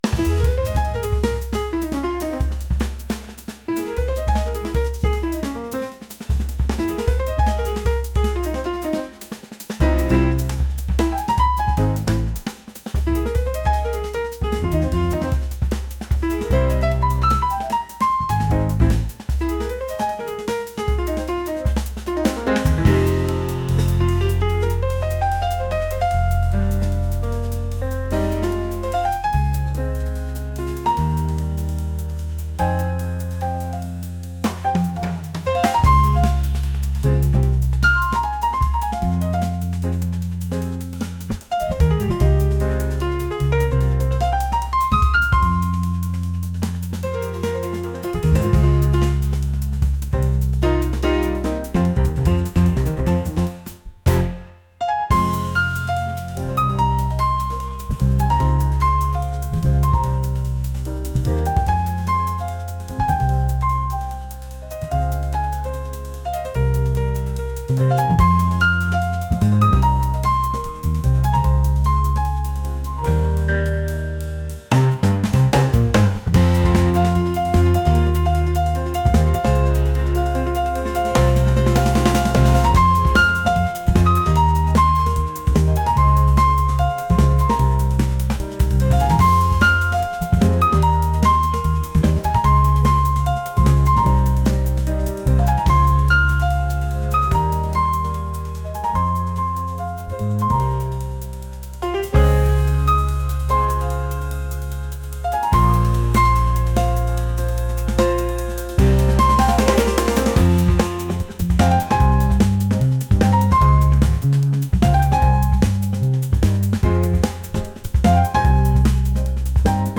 jazz | energetic